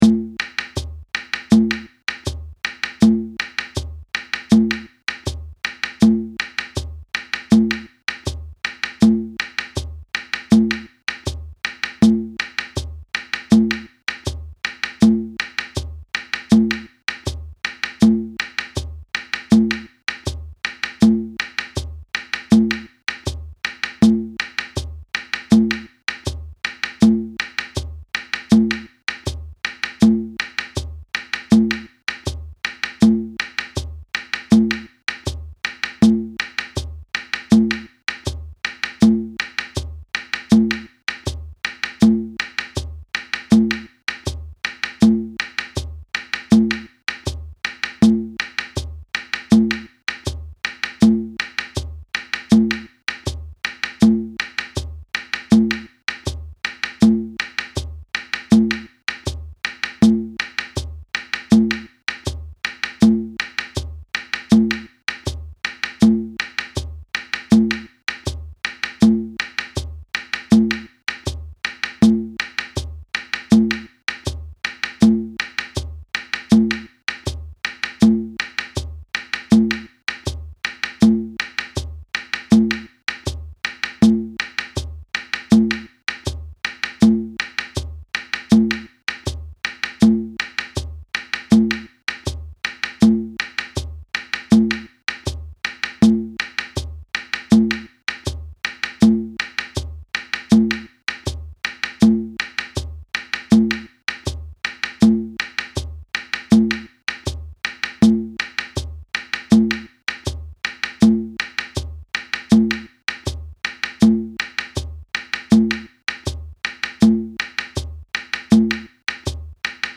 A drum circle favorite, this electric rhythm incorporates West African and Brazilian rhythm concepts (in 4/4 time).
low part audio (with shekeré)
West-African-Samba-low-hh.mp3